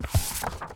x_enchanting_scroll.14.ogg